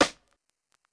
Snare Layered MASSA.wav